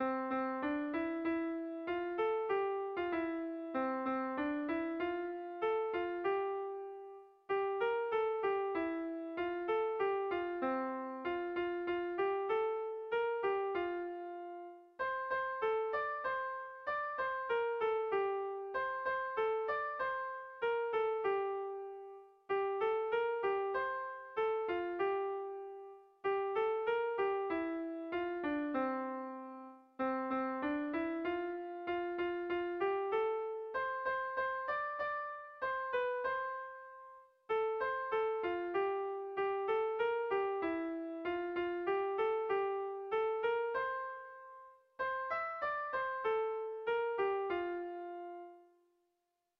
Air de bertsos - Voir fiche   Pour savoir plus sur cette section
Sentimenduzkoa
ABDEF